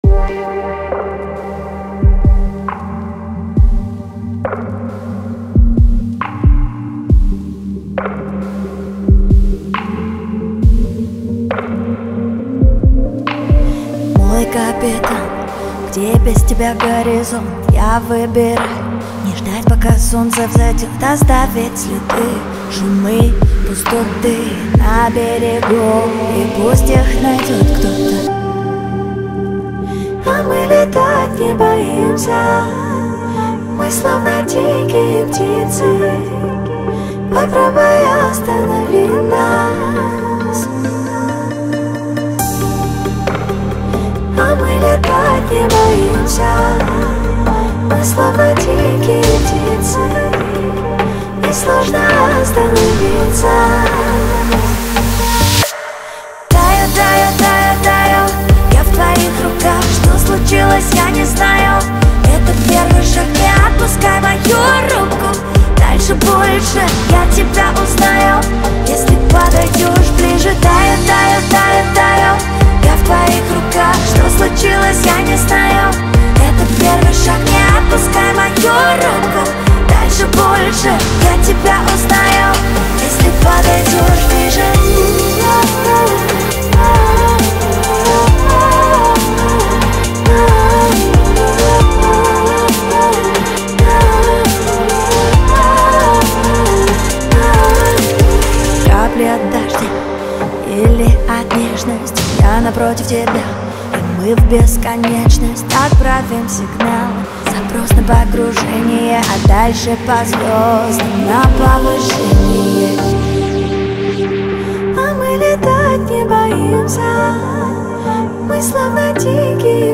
сочетает ритмичные мотивы с яркими образами